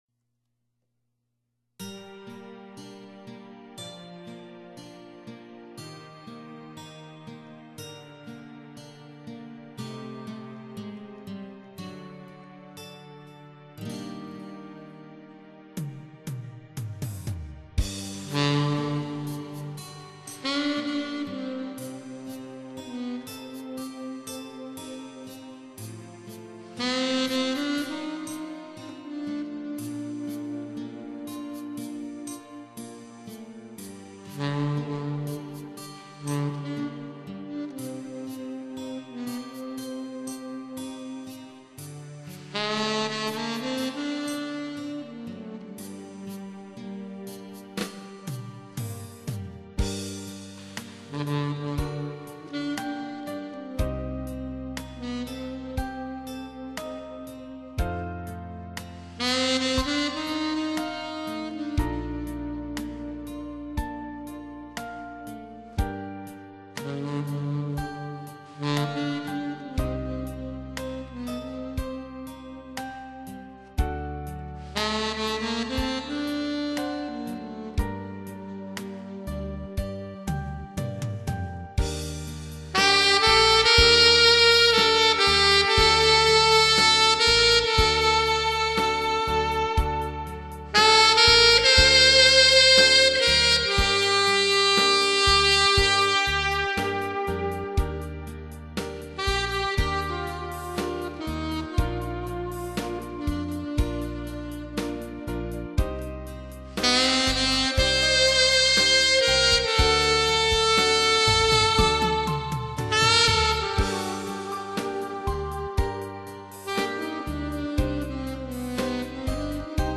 YAS-875커스텀, 듀콥 D8호메탈, 라보즈
아마추어의 색소폰 연주